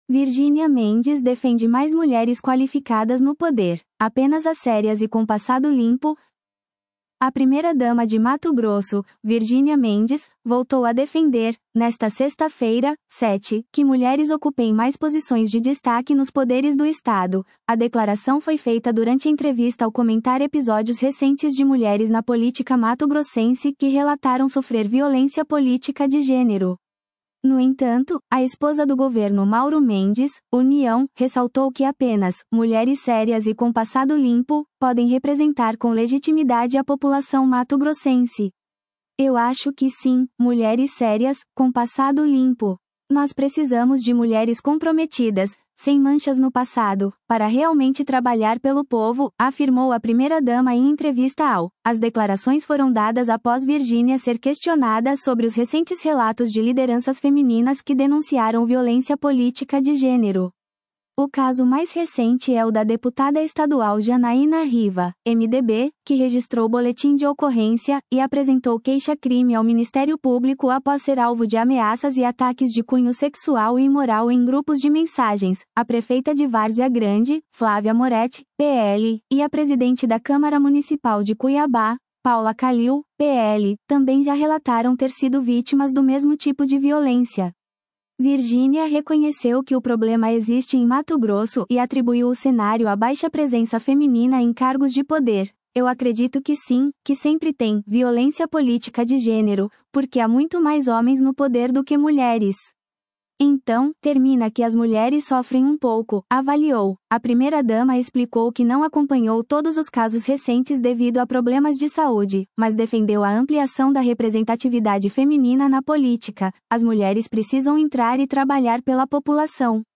A declaração foi feita durante entrevista ao comentar episódios recentes de mulheres na política mato-grossense que relataram sofrer violência política de gênero.